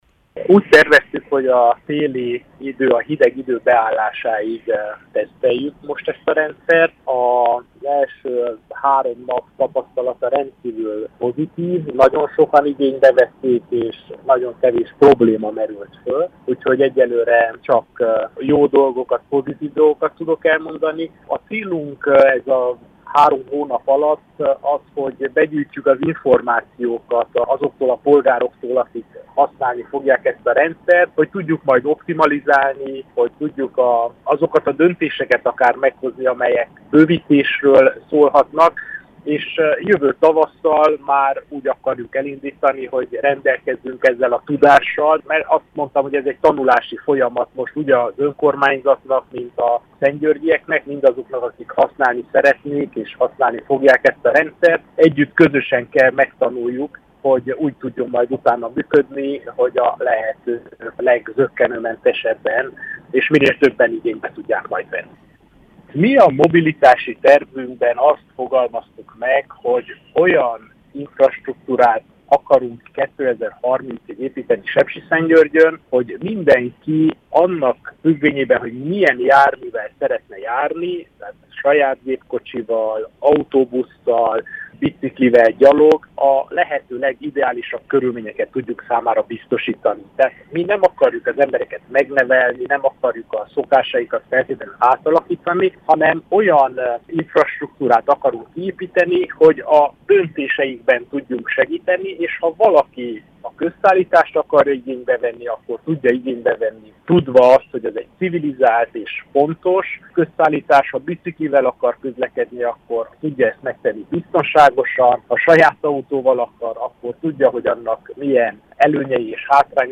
A városvezető a Marosvásárhelyi Rádiónak hangsúlyozta: az önkormányzatnak nem az a dolga, hogy megszabja ki, mivel közlekedjen, hanem hogy mindenki számára megfelelő körülményeket teremtsen.